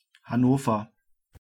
Hanover (/ˈhænvər, -nəv-/ HAN-oh-vər, HAN-ə-vər; German: Hannover [haˈnoːfɐ]
Recent editions of Encyclopædia Britannica prefer the German spelling,[a] and the local government uses the German spelling on their English webpages.[8] The English pronunciation, with stress on the first syllable, is applied to both the German and English spellings, which is different from German pronunciation, with stress on the second syllable and a long second vowel.